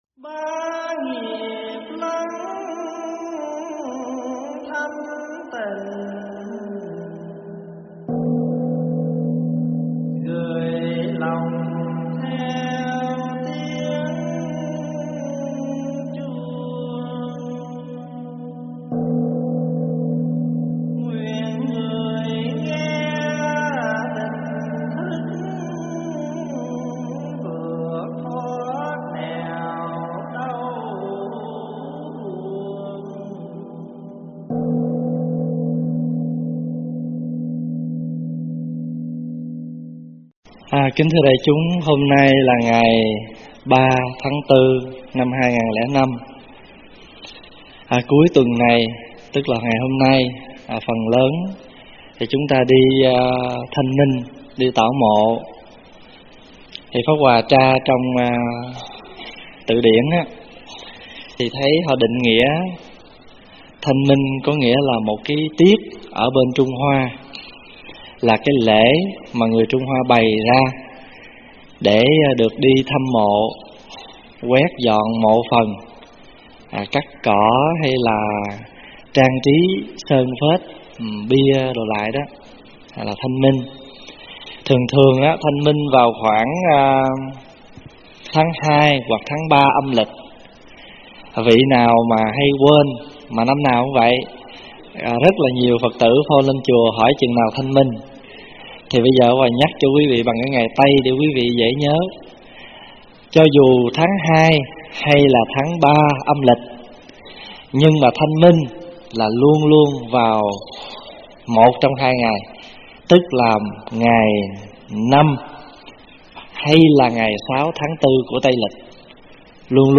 thuyết pháp
giảng tại tu viện Tây Thiên